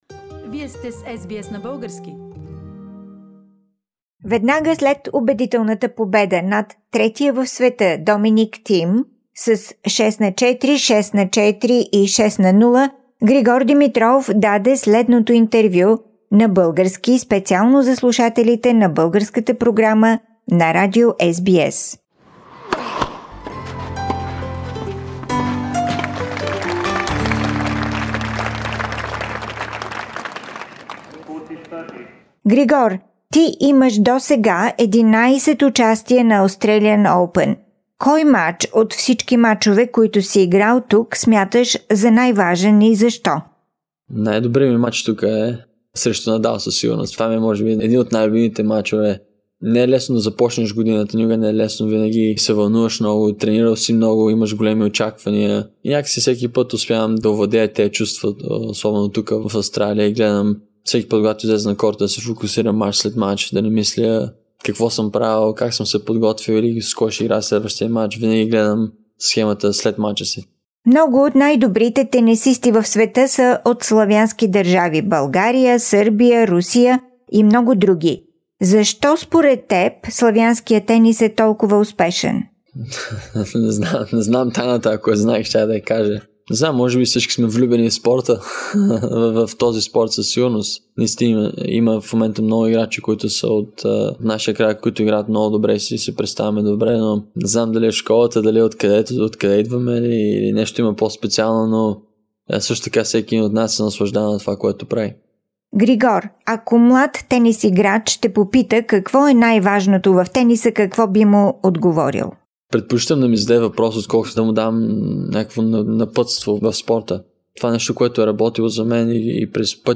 Interview with Grigor Dimitrov after his win over Dominic Thiem in the Australian Open
After the match with Thiem, Grigor gave an exclusive interview for the Bulgarian program on SBS Radio.